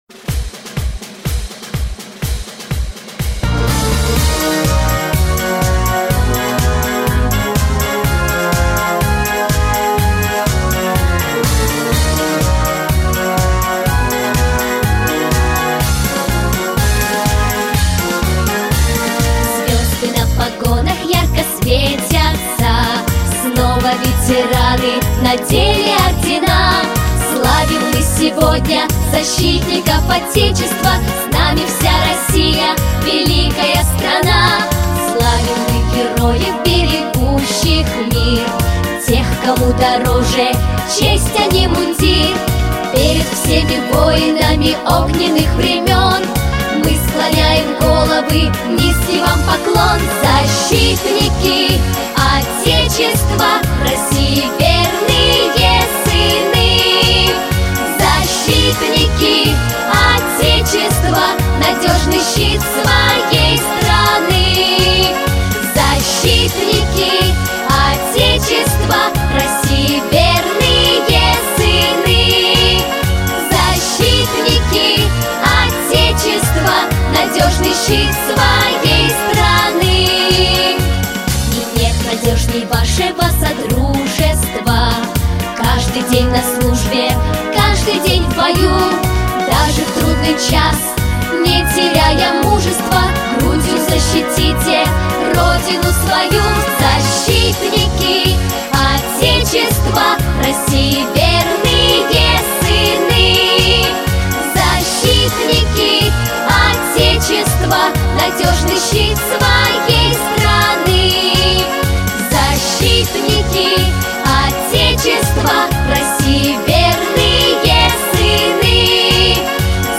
праздничная песня